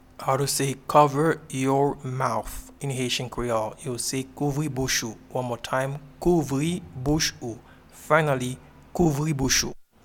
Pronunciation and Transcript:
Cover-your-mouth-in-Haitian-Creole-Kouvri-bouch-ou.mp3